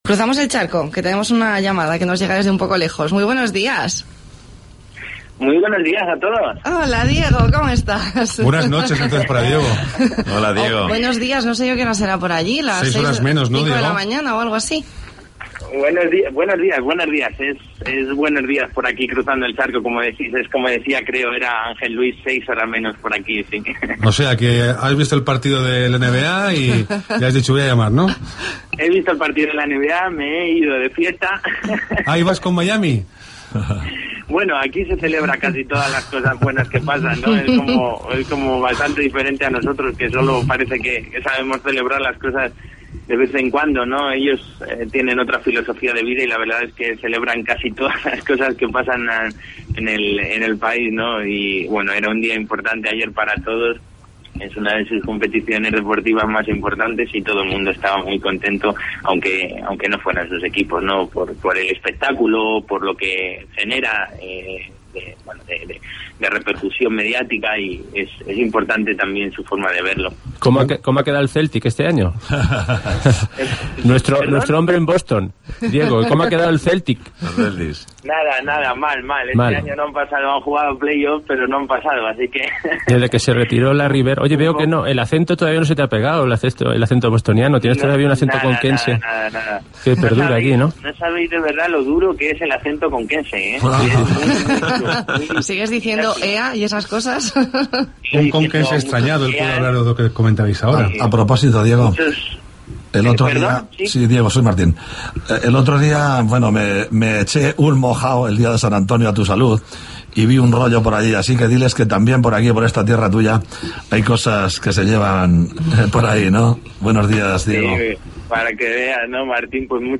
Despedida tertulianos Vox Populi